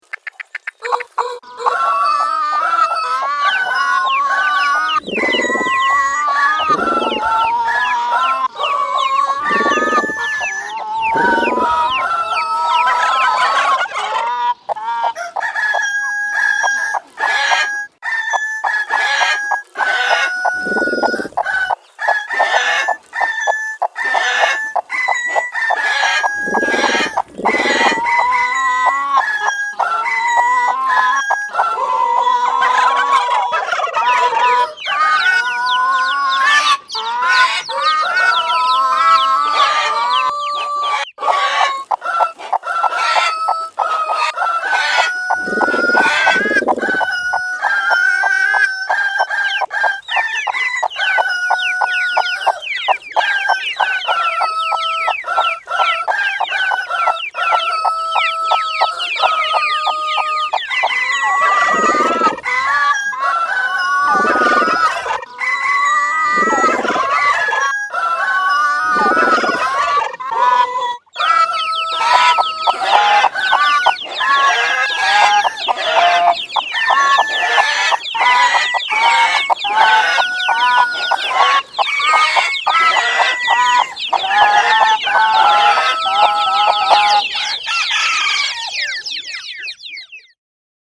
arrangement pour coq et basse-cour